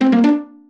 jingles-pizzicato_04.ogg